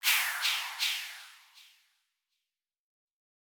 Boomin - FX - 2.wav